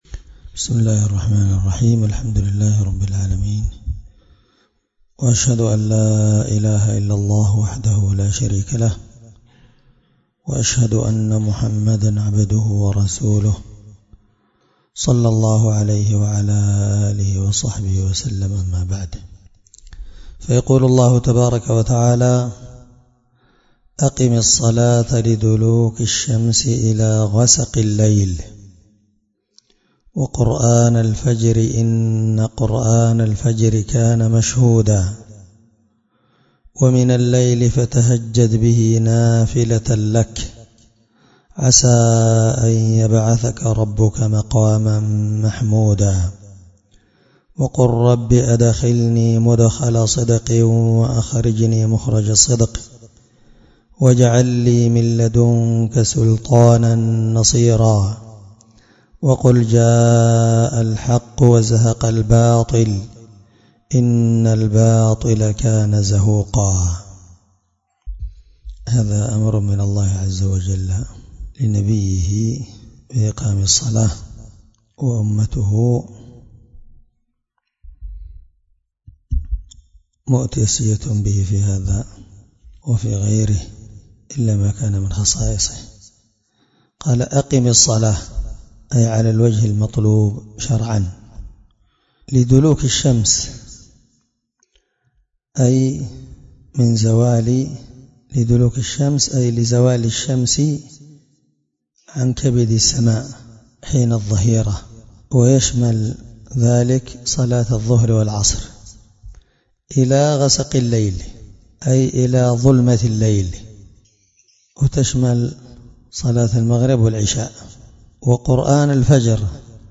الدرس24 تفسير آية (78-81) من سورة الإسراء